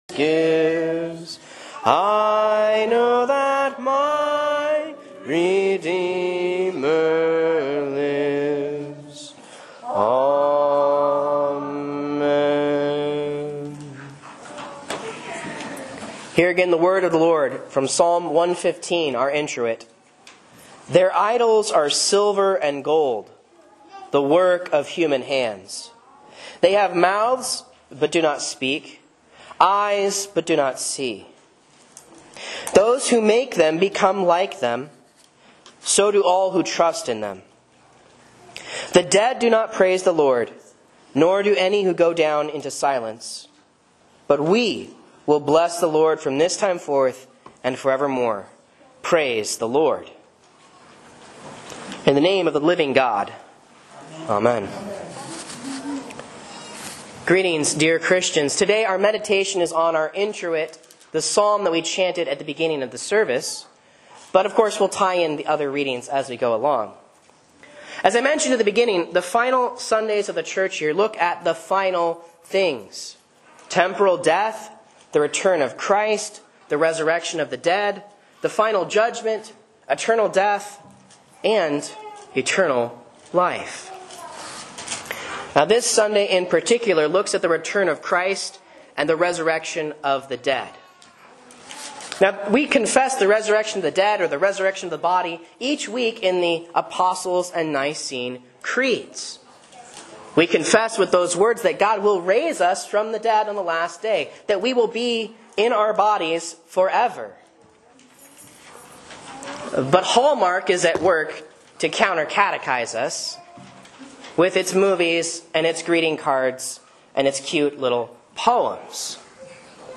A Sermon on Psalm 115:17-18 for Proper 27 (C)